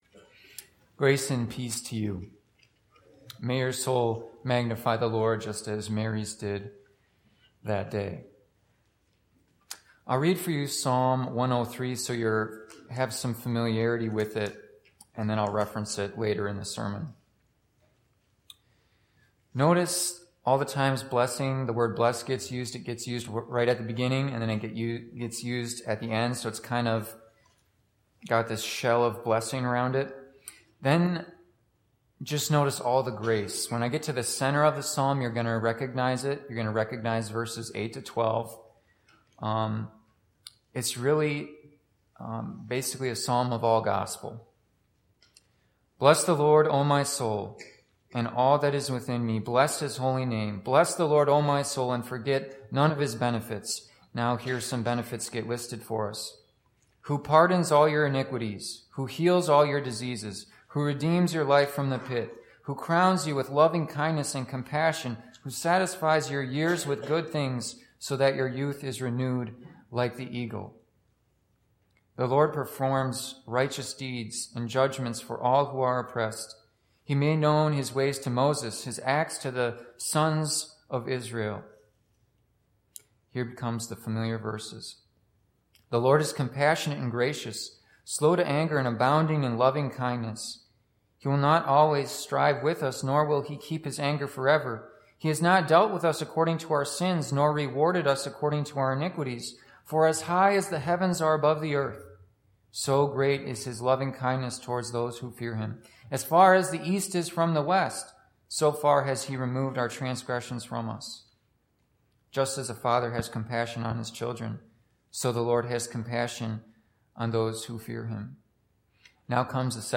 Services (the most recent service is in the first box)